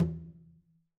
Conga-HitN_v3_rr2_Sum.wav